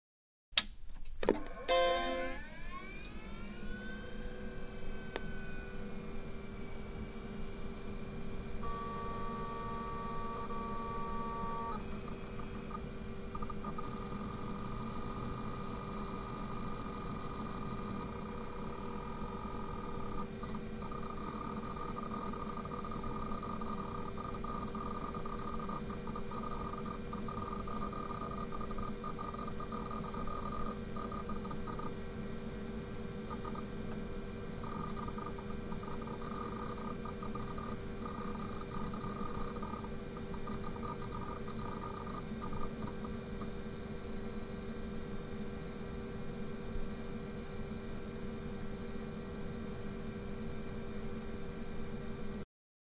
Звук ушедшей компьютерной эпохи